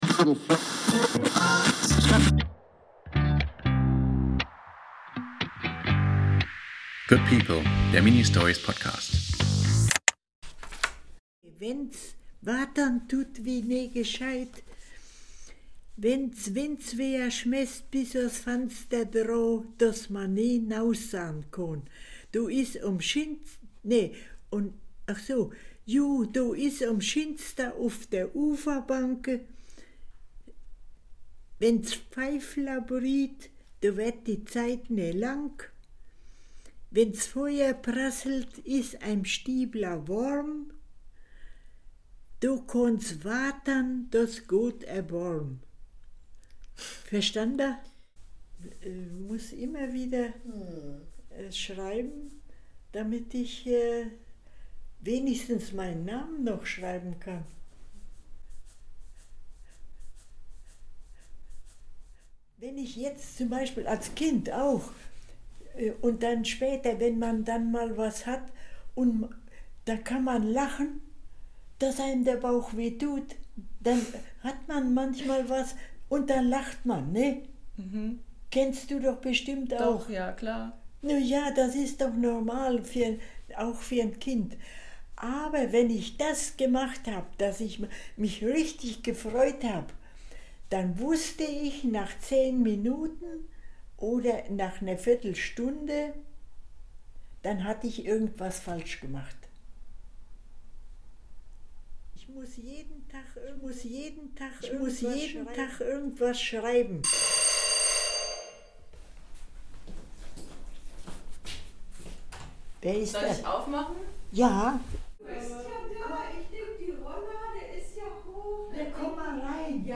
Wir erzählen kurze und kürzeste Geschichten über Menschen, die einander helfen, unterstützen, vertrauen, die etwas wagen, die trösten und lieben, die uns überraschen, ihre Schmerzen und ihr Glück zeigen, die uns unerwartet entgegenkommen, die uns retten und ermutigen, die zuhören, ihr Wissen teilen, freundlich herüberschauen, die freiwillig etwas tun, die unbequem sind und damit Freiräume schaffen, die sich nicht so wichtig nehmen, die sich erinnern, die hoffen und träumen, die alt sind und für die Jungen da sind, die noch klein sind und für die Alten da sind, die uns beschützen, die mit uns springen und die mit uns sprechen. In schneller Folge entstehen Mini-Portraits von vielen verschiedenen Menschen.